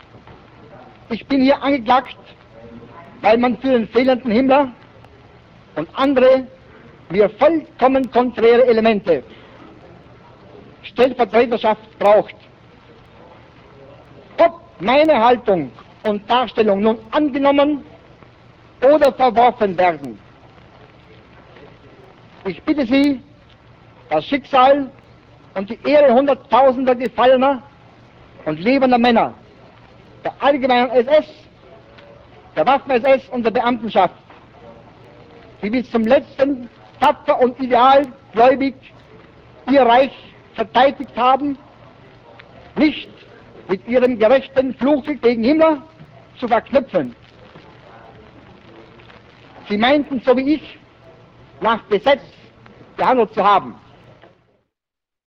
뉘른베르크 재판 당시 육성